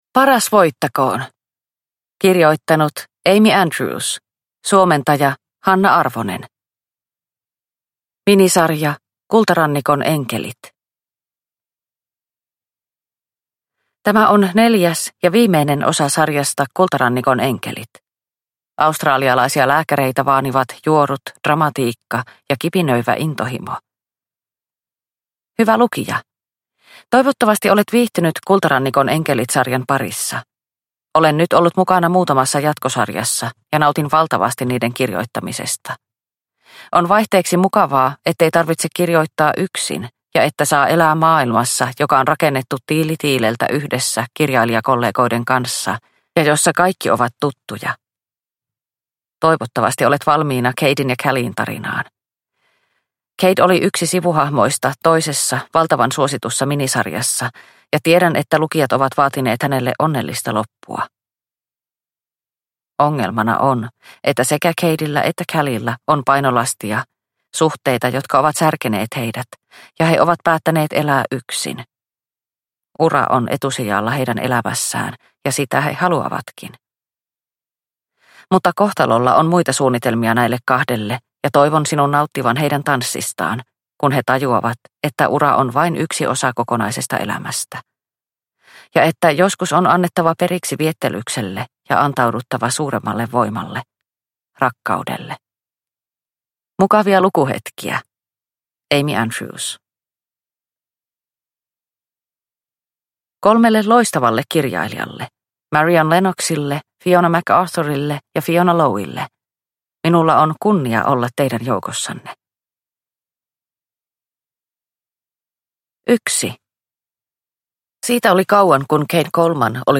Paras voittakoon (ljudbok) av Amy Andrews